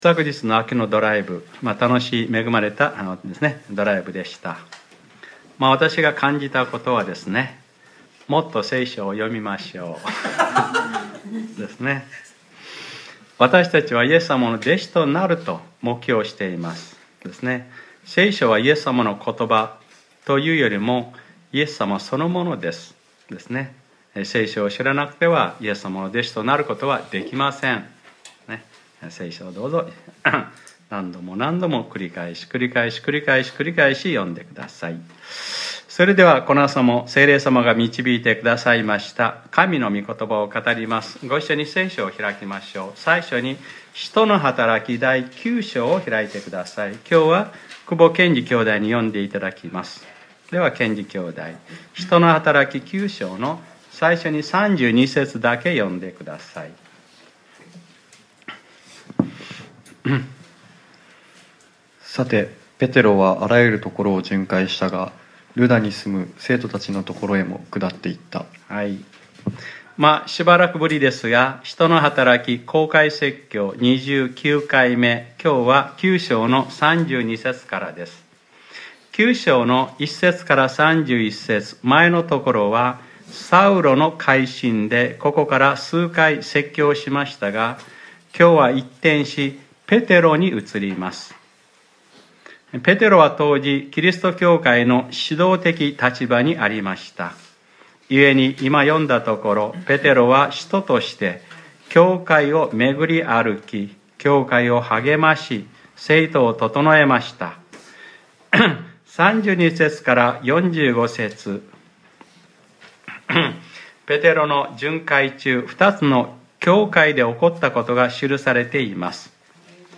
2018年11月25日（日）礼拝説教『タビタ、起きなさい』